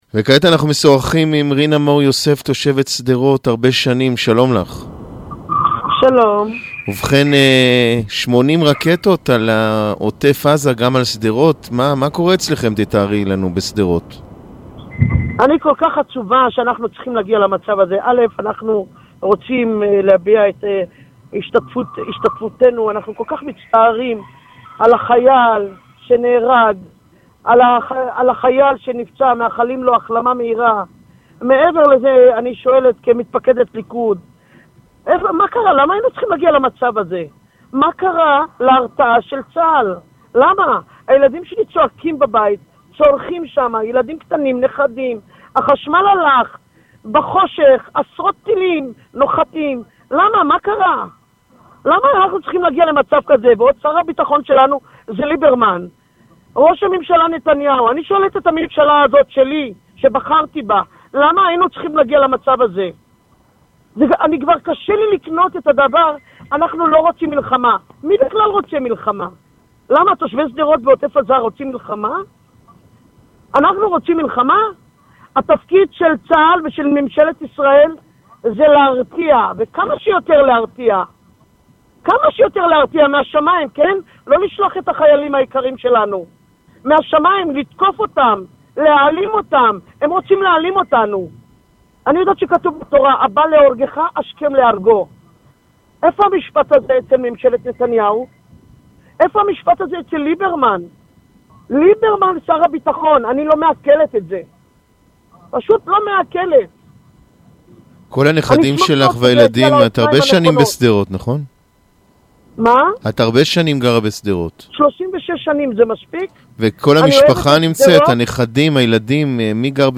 Аудиозапись интервью